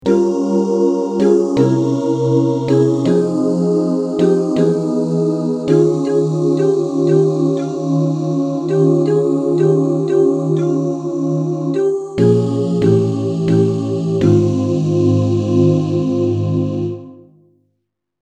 Key written in: F Minor
How many parts: 4
Type: Other mixed
Comments: Take this at a nice easy ballad tempo.
All Parts mix: